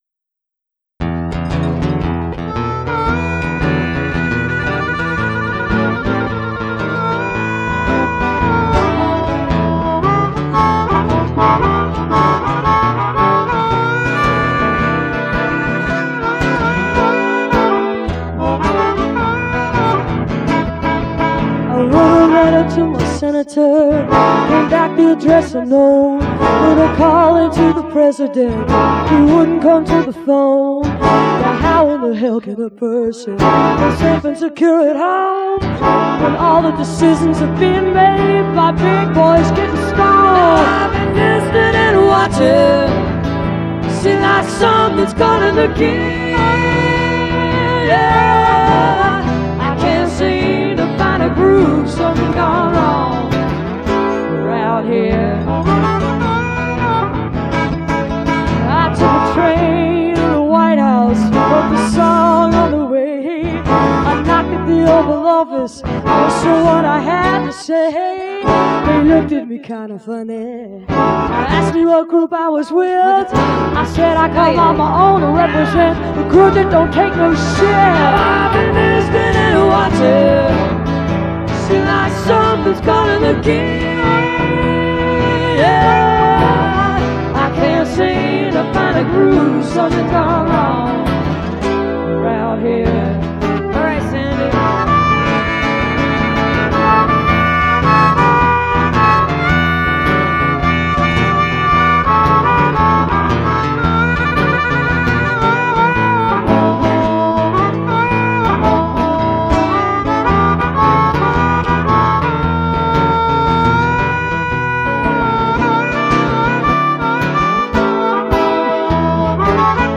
9-12 various live in 1987